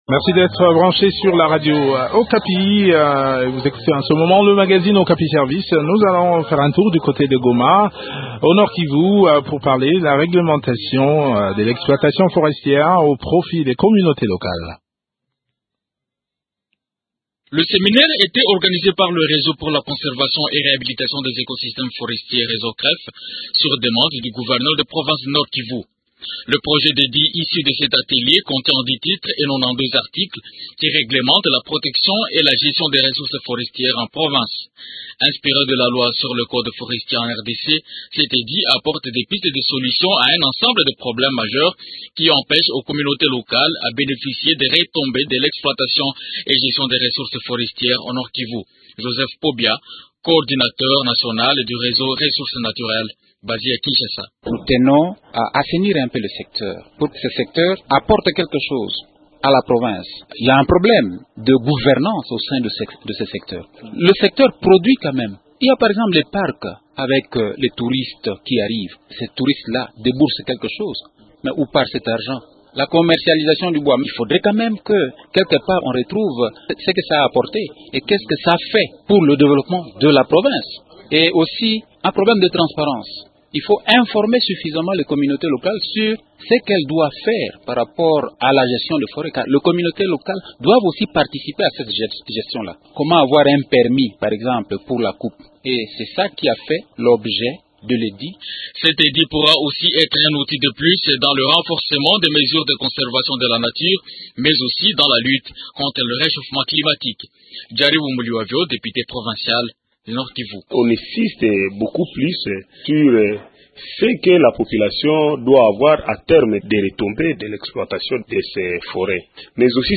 Eléments de réponse dans cet entretien